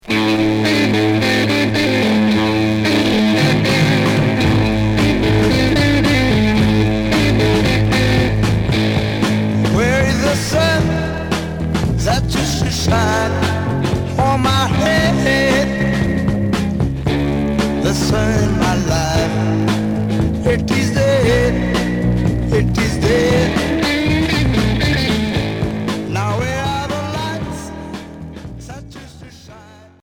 Heavy rock